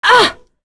Priscilla-Vox_Damage_04.wav